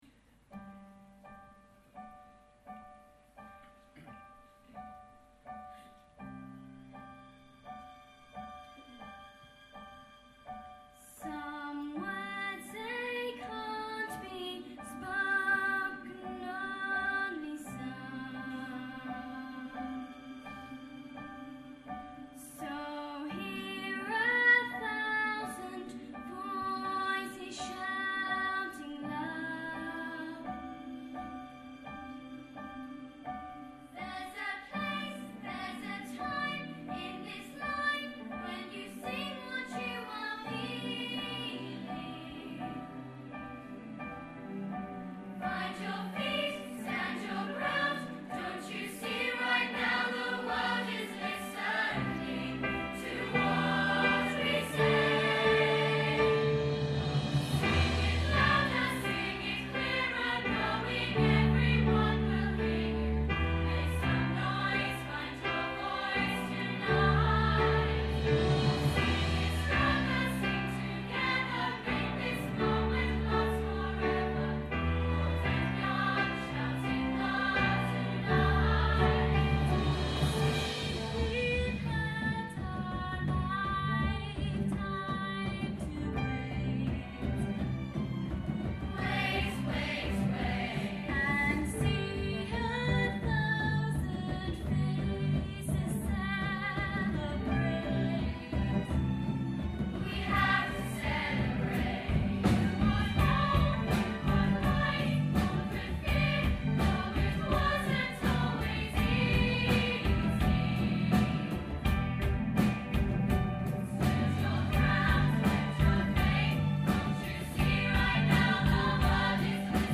'Sing' performed by all our choirs
Including the orchestra, Coro, Young Voices, Take Note, Saxidentals and more